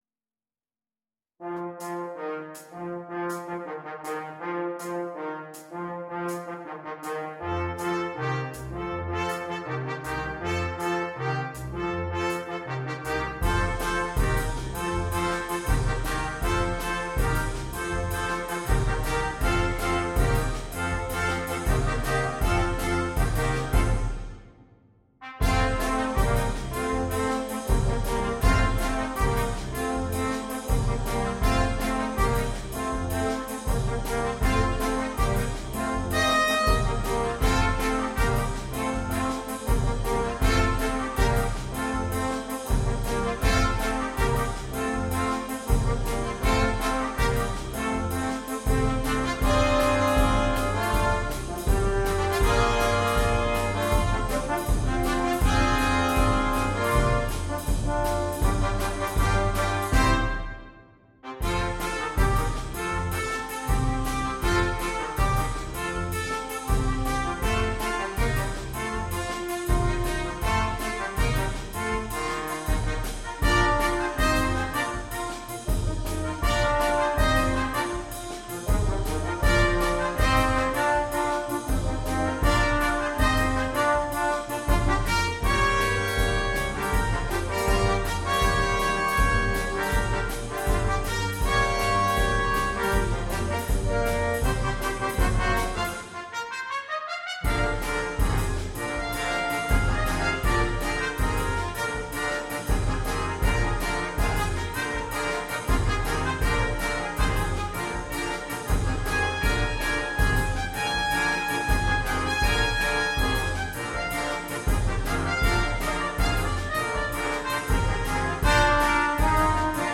для брасс-бэнда.